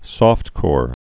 (sôftkôr, sŏft-)